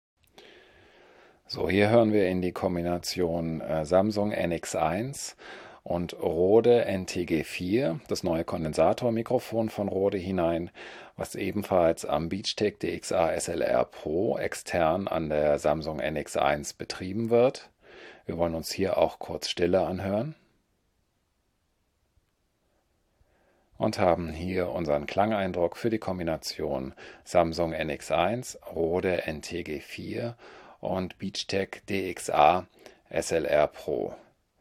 Wir haben bei den folgenden Tonbeispielen den internen Audio-Pegel der Samsung NX1 auf 1 gelassen und die jeweiligen externen Verstärker die Hauptverstärkerarbeit verrichten lassen.
Hier die normalisierte Version:
Samsung NX1 mit Beachtek DXA-SLR PRO und Rode NTG4 (Kondensator Richtmikro Batteriebetrieb)
SamsungNX1_NTG4Rode_norm.wav